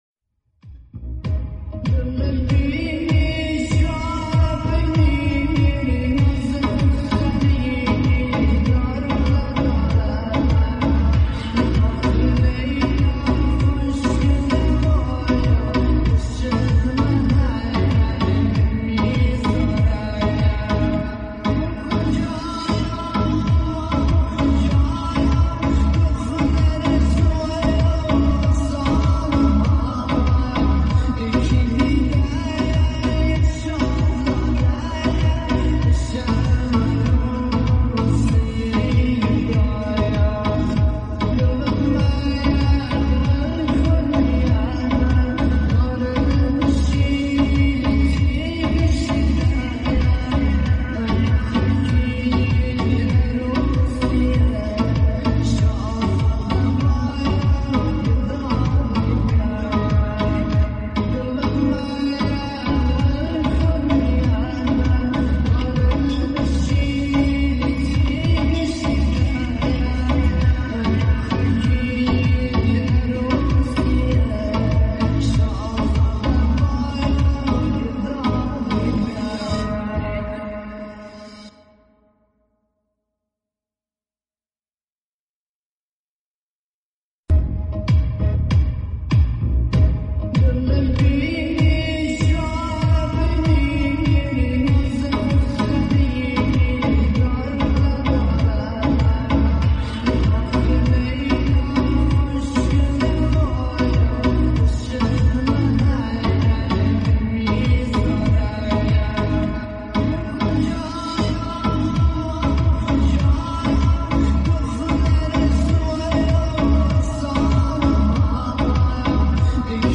Full Slow And Reverb